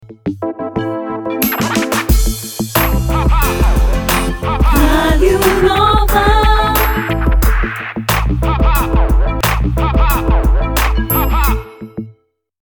Portuguese Female Voiceover
Bumper